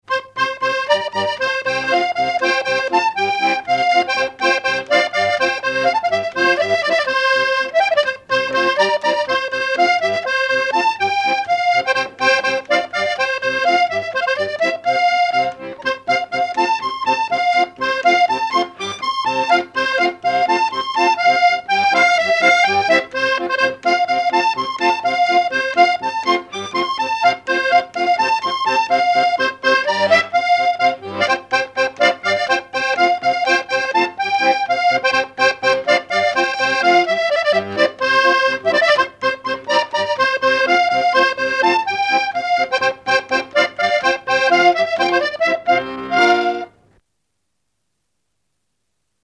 Enregistrement original de l'édition sur disque vinyle
musique pour les assauts de danse et le bal.
accordéon(s), accordéoniste ; musique traditionnelle
danse : quadrille
Répertoire des danses à l'accordéon diatonique
Pièce musicale inédite